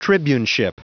Prononciation du mot tribuneship en anglais (fichier audio)
Prononciation du mot : tribuneship